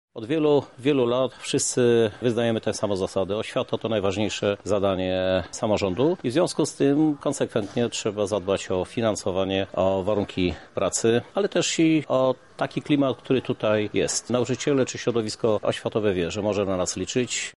-mówi prezydent Lublina, Krzysztof Żuk.